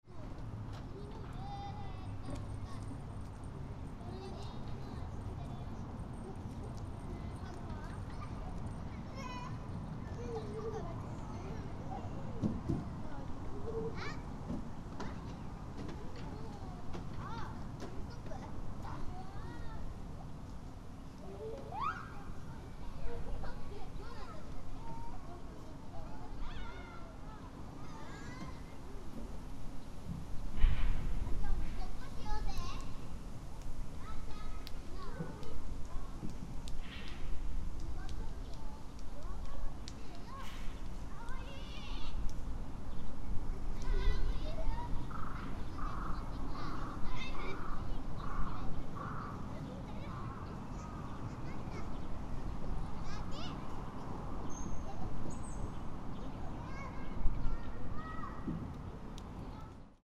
Even though the weather was extremely hot in April, many children were playing cheerfully in Shinhama Park. ♦ Some adults were sitting on the benches for rest.